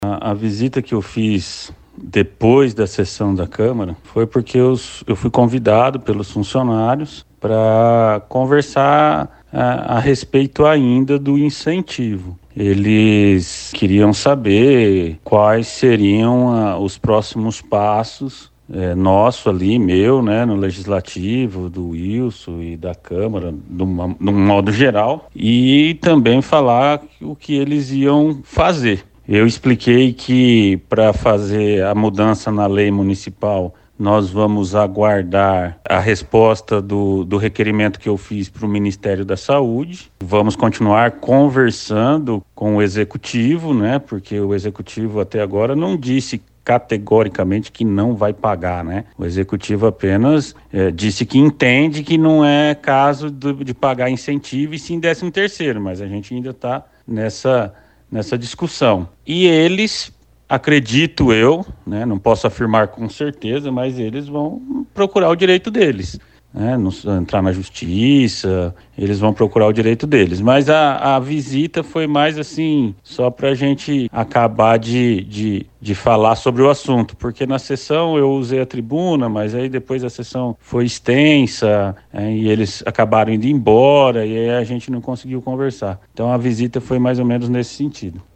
Ouçam o que disse o vereador sobre a reunião: Aperte o play: